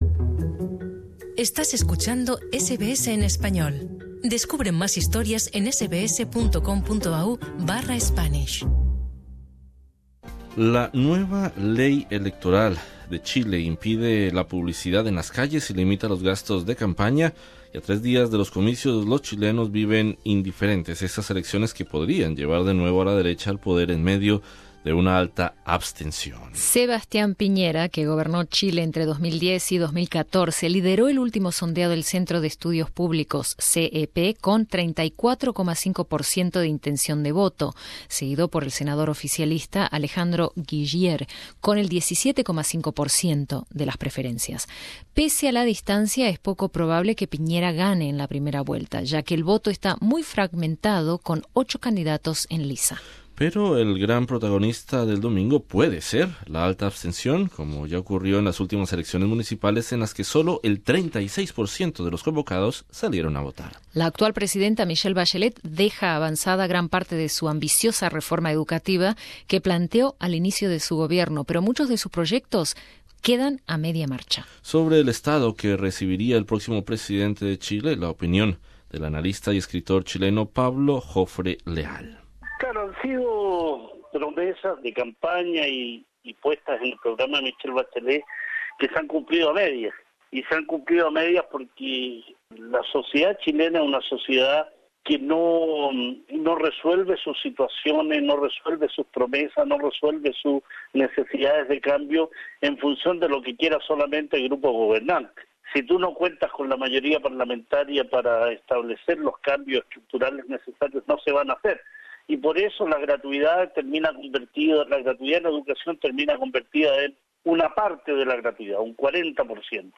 Y para hablar sobre el voto en el exterior, conversamos con el Cónsul general de Chile en Sidney, Humberto Molina.